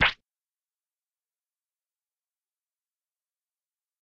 jumpstrong.ogg